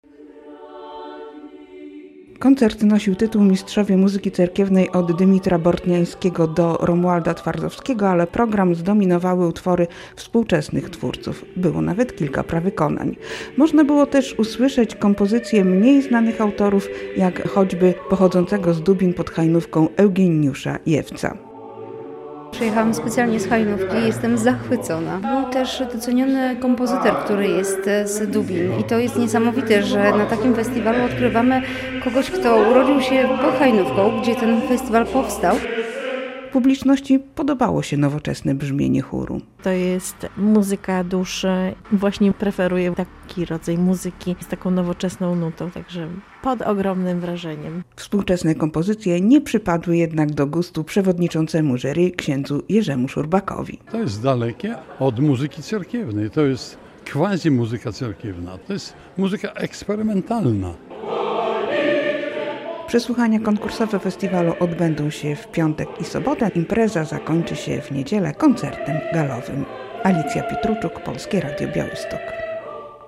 Koncert w wykonaniu Państwowego Litewskiego Chóru Kameralnego "Polifonia" z Siaulai zainaugurował 44. Międzynarodowy Festiwal Muzyki Cerkiewnej "Hajnówka 2025" w Białymstoku.